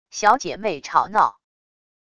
小姐妹吵闹wav音频